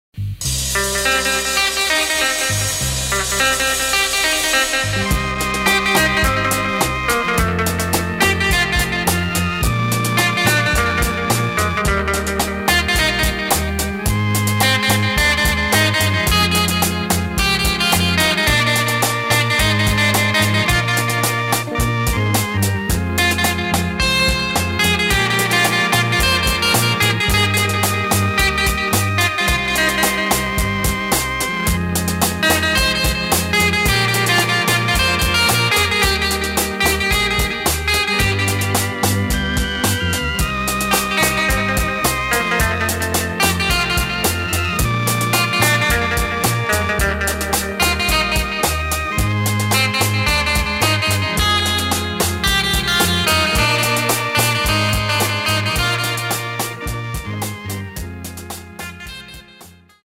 4.0 PERUVIAN INSTRUMENTALS FROM 60's